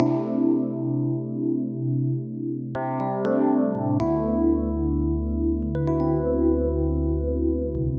hold_music
Dreamy.mp3